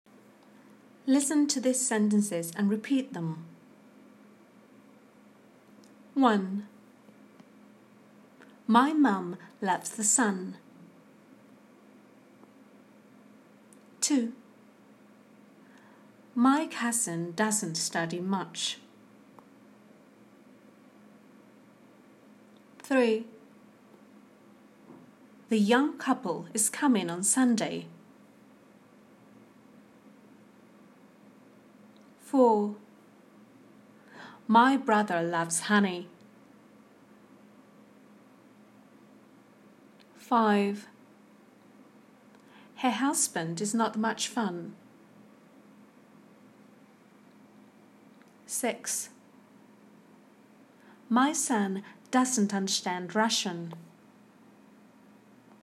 PRONUNCIATION: Pronouncing /ʌ/